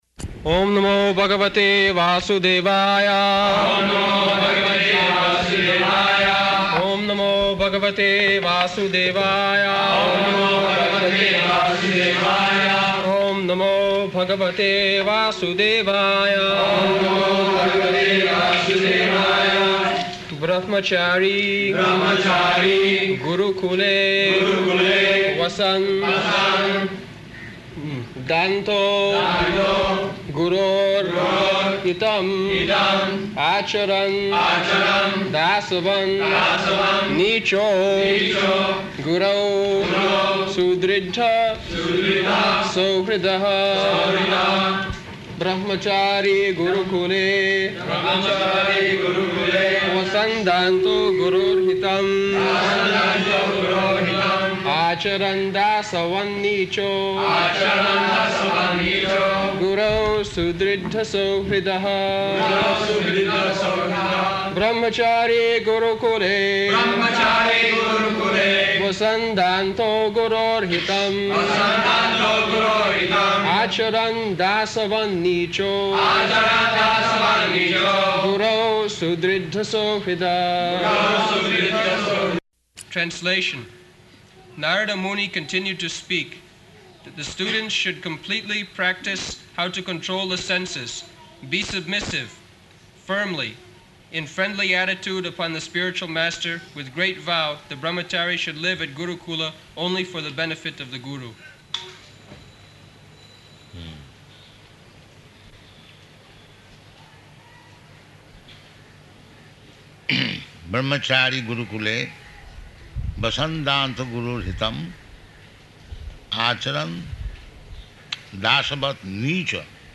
-- Type: Srimad-Bhagavatam Dated: April 12th 1976 Location: Bombay Audio file
[chants verse; devotees respond]